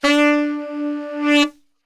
D 2 SAXSWL.wav